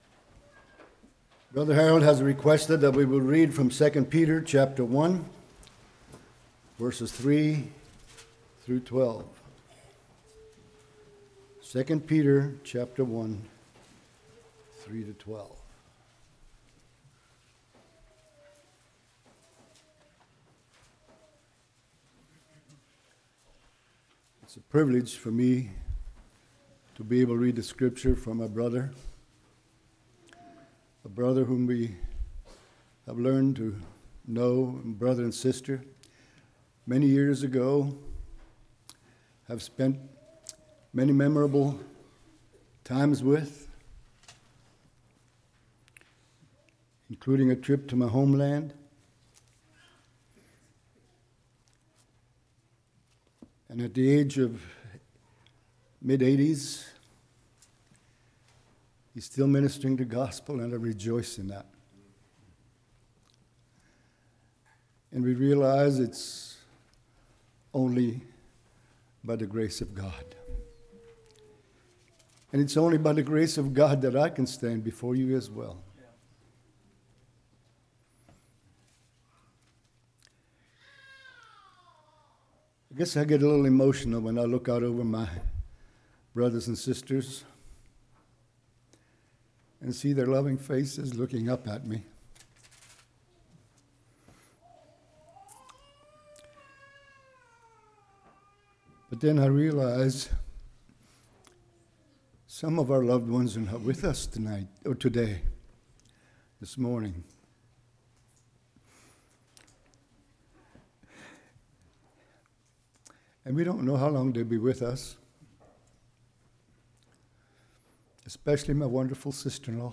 2 Peter 1:3-12 Service Type: Morning Virtue Knowledge Temperance Patience Godliness Brotherly Kindness Charity « Hymn Sing Doctrine »